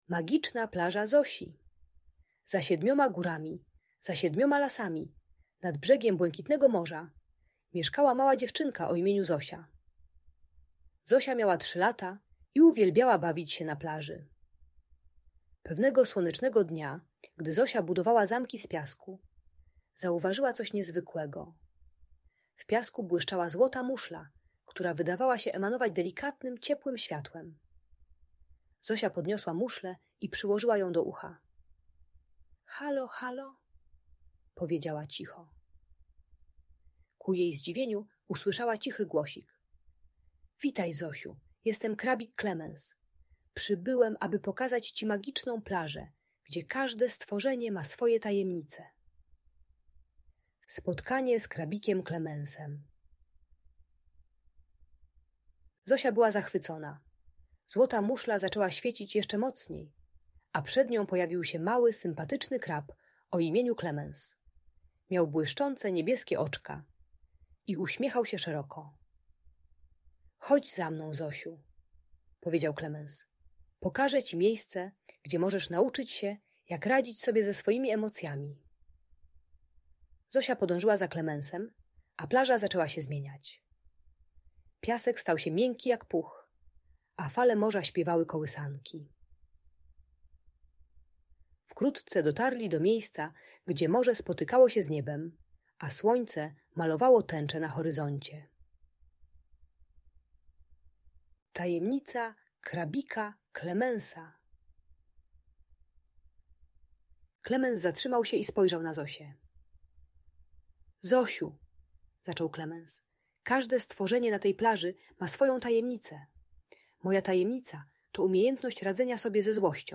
Magiczna Plaża Zosi - Audiobajka